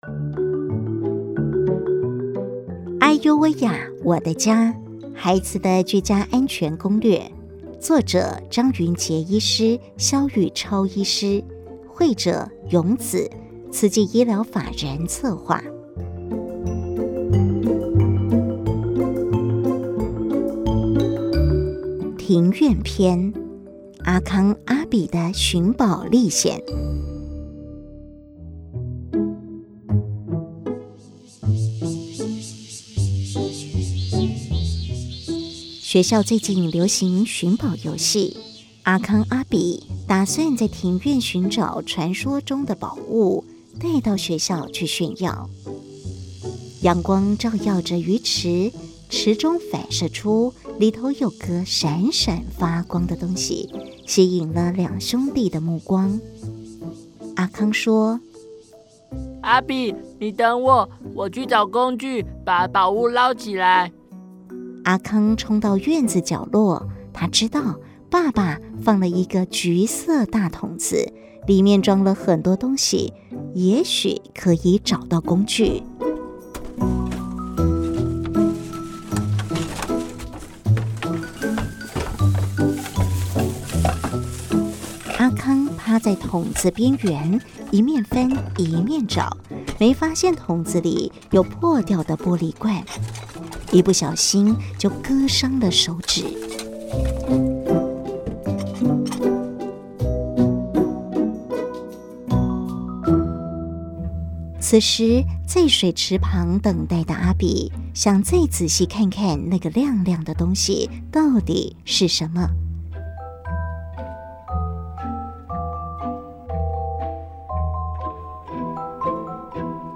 《唉唷喂呀，我的家──孩子的居家安全攻略》有聲書